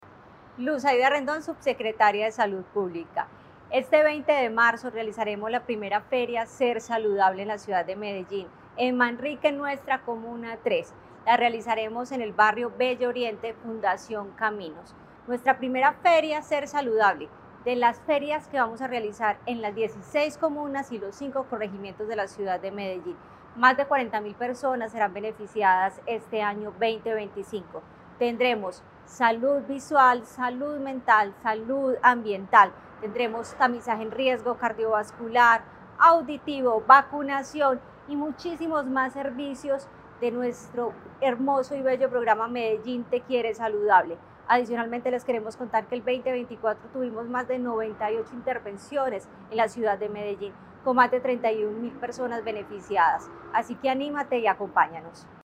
Palabras de subsecretaria de Salud Pública Luz Aida Rendón Berrío
Palabras-de-subsecretaria-de-Salud-Publica-Luz-Aida-Rendon-Berrio.mp3